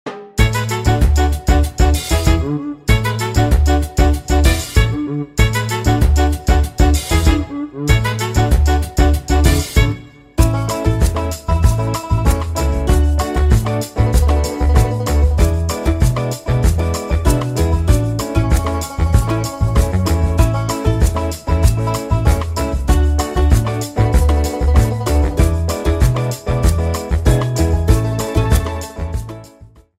The remastered theme